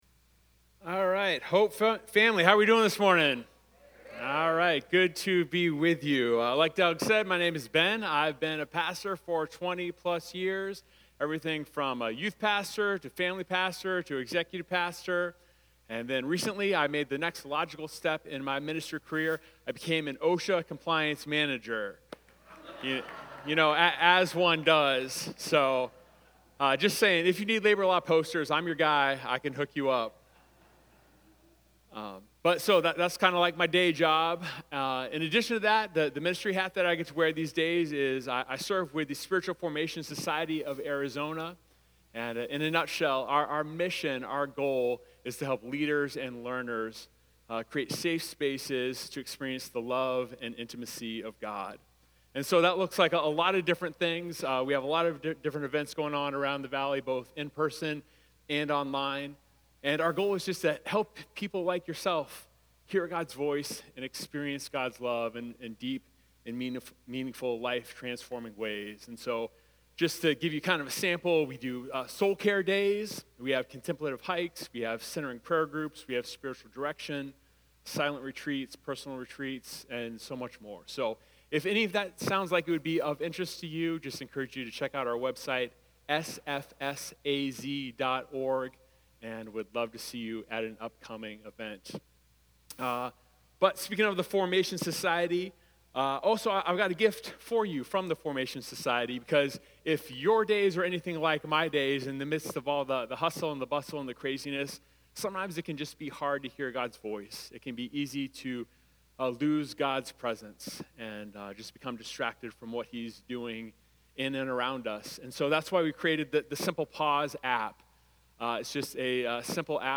Sermons from Hope Covenant Church: Chandler AZ